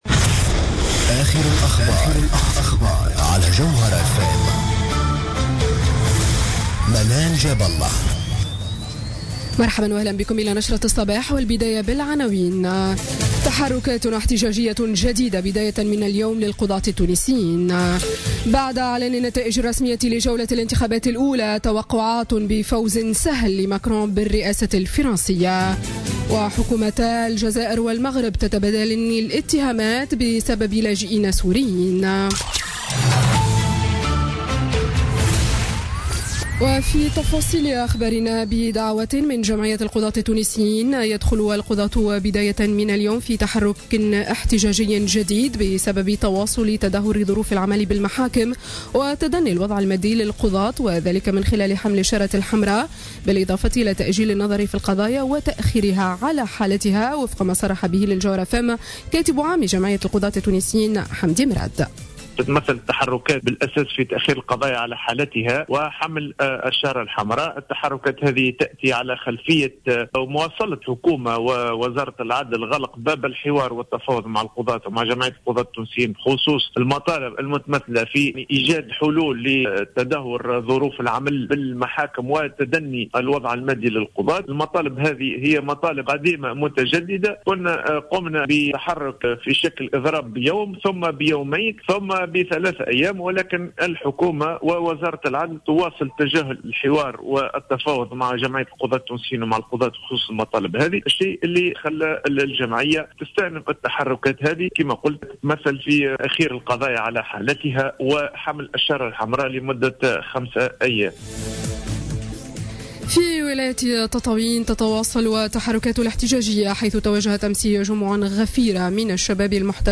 نشرة أخبار السابعة صباحا ليوم الإثنين 24 أفريل 2017